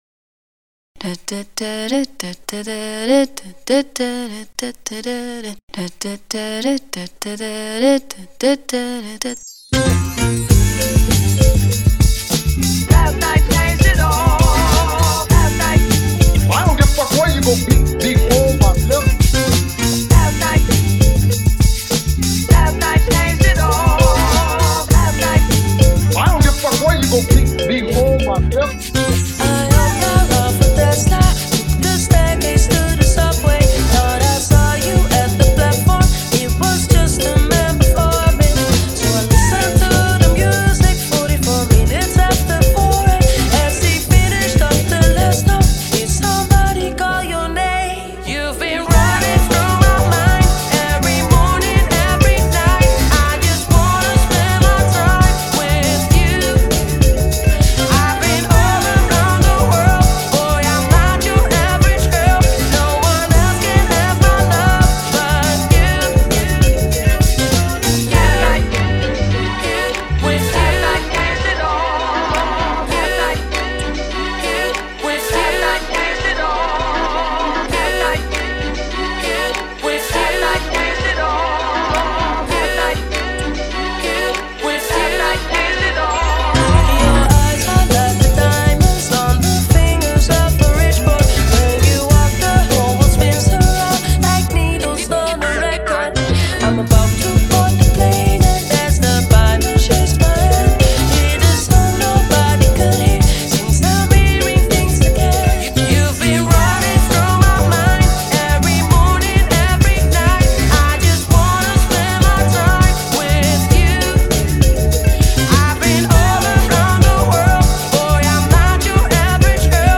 をサンプリングした同ネタ使い2曲をブレンド！